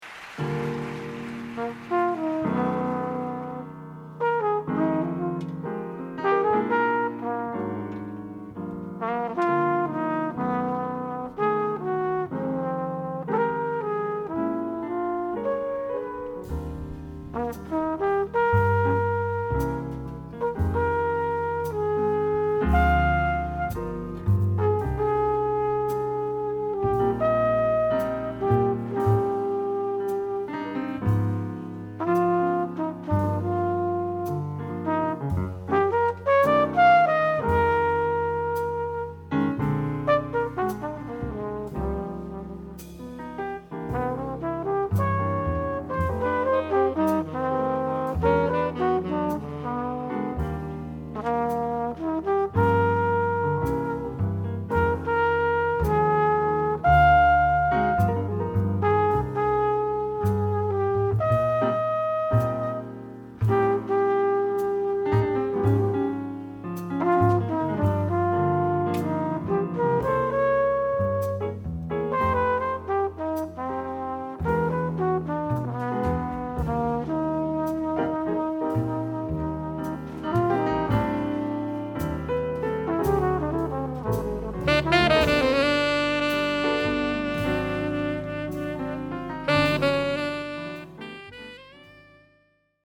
Genres: Jazz, Live.